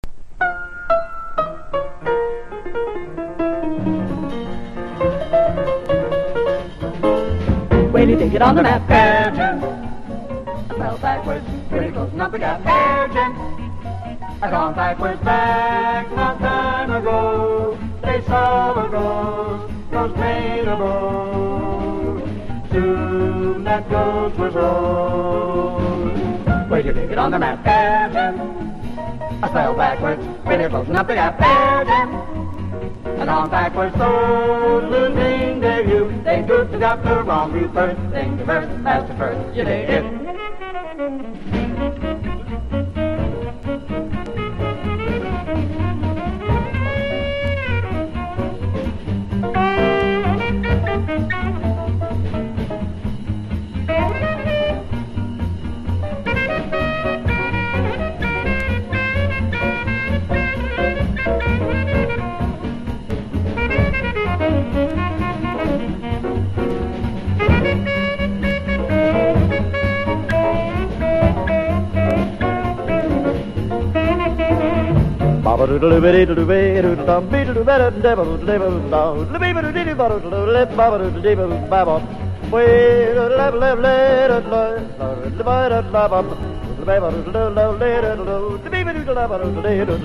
スタンダードナンバーをスキャットでカヴァーした名作!!